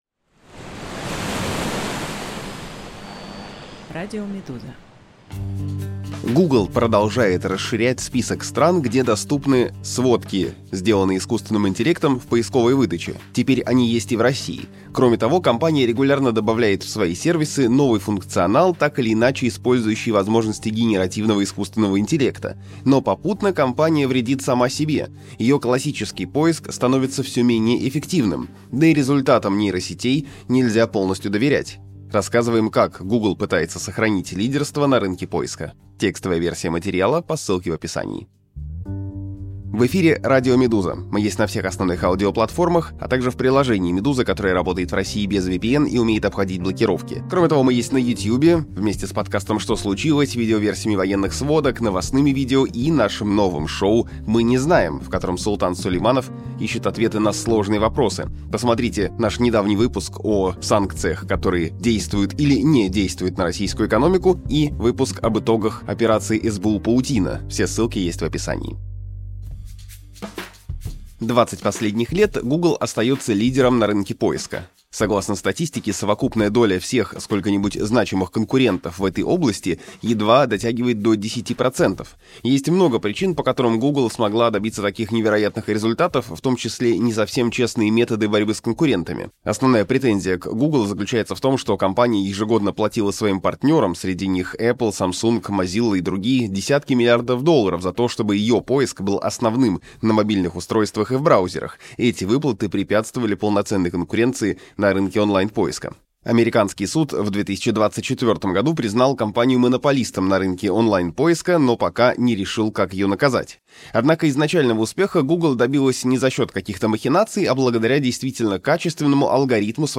Несмотря на всю критику, творчество Церетели — настоящее постмодернистское искусство, рассказывает кооператив независимых журналистов «Берег». Послушайте аудиоверсию этого текста.